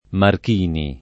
[ mark & ni ]